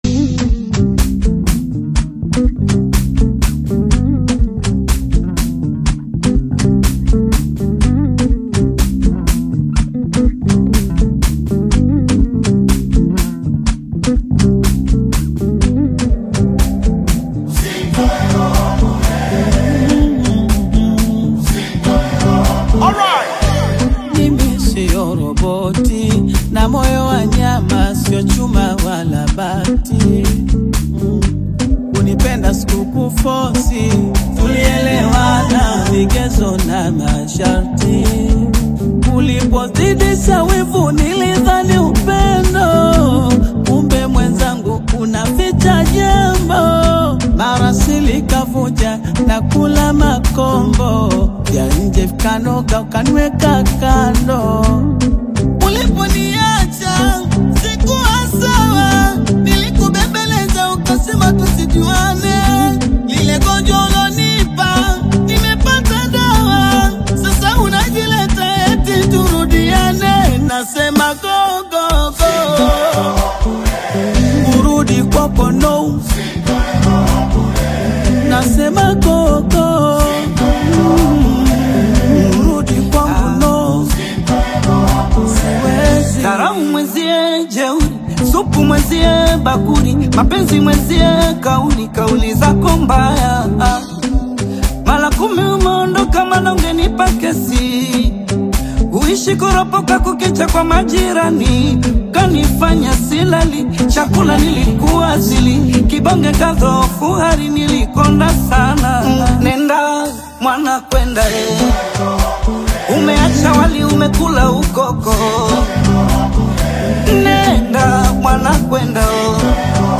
dansi ensemble
emotive Swahili lyrics about love and heartbreak